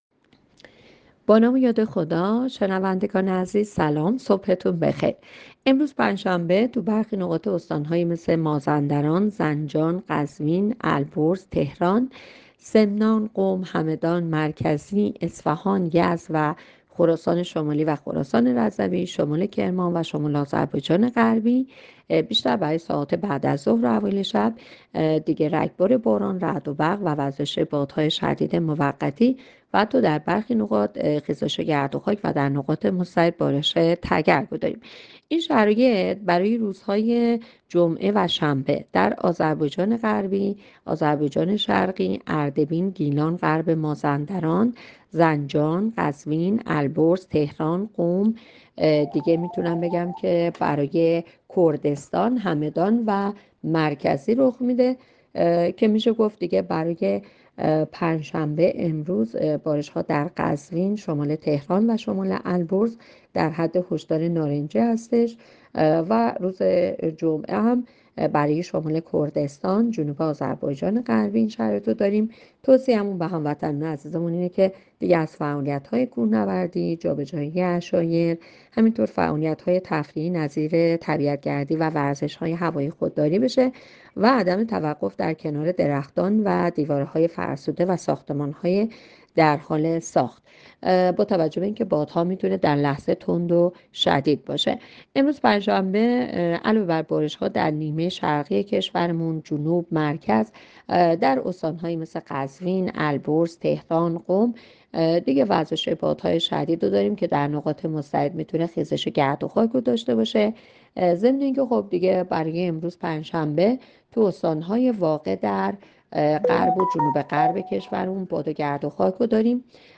گزارش رادیو اینترنتی پایگاه‌ خبری از آخرین وضعیت آب‌وهوای یازدهم اردیبهشت؛